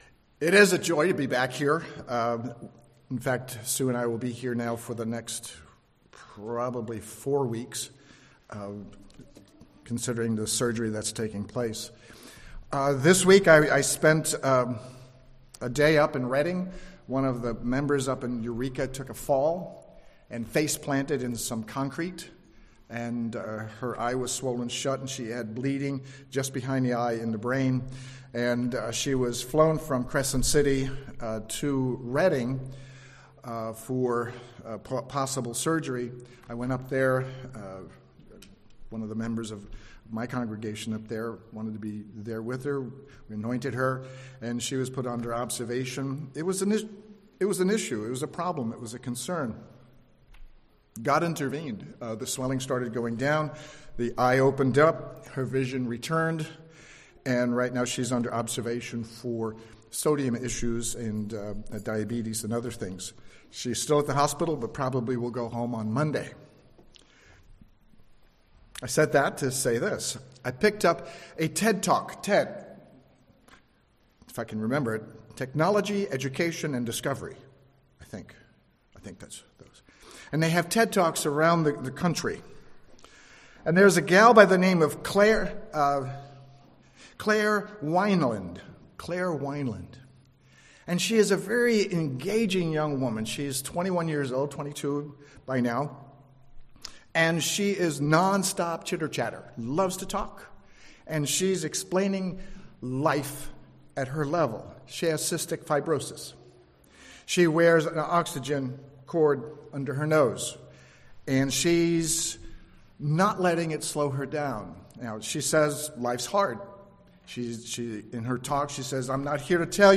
Listen to this sermon and learn about God's promise of eternal life, and find out what we should do to inherit eternal life.